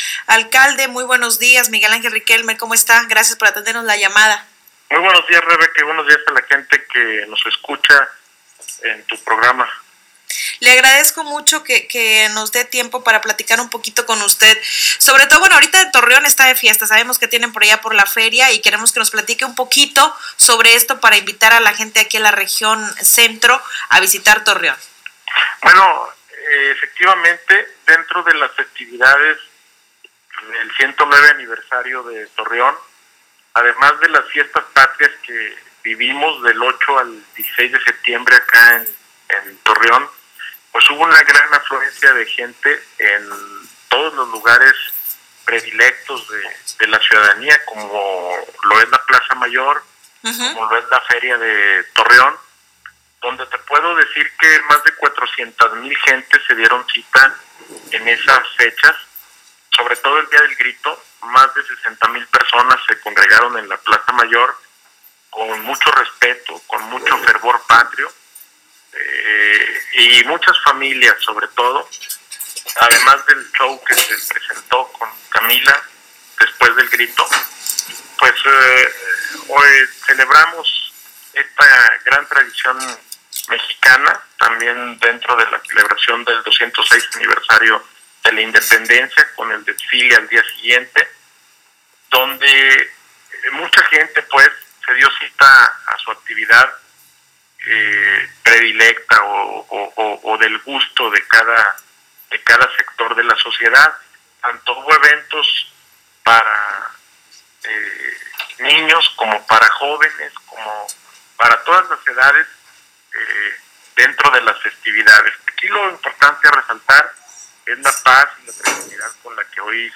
Entrevista-Riquelme-ok.m4a